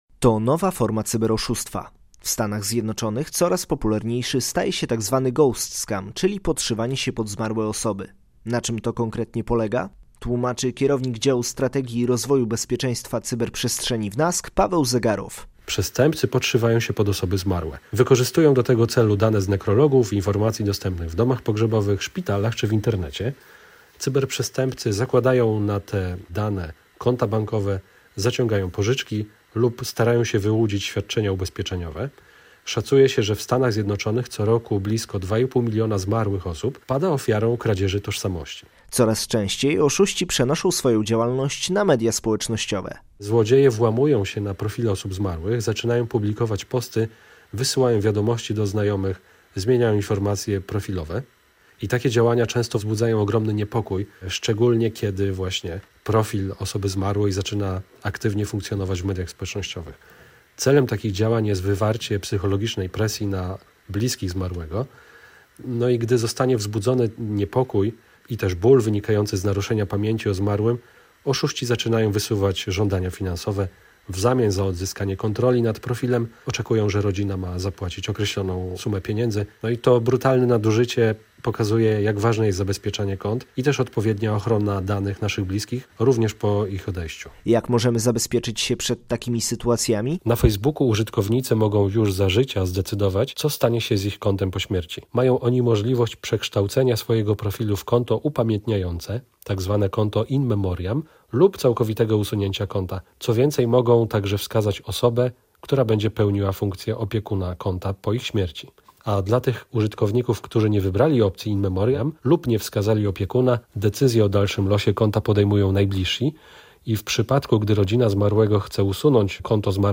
Czym jest ghost scam - audycja